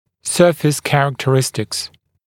[‘sɜːfɪs ˌkærəktə’rɪstɪks][‘сё:фис ˌкэрэктэ’ристикс]свойства поверхности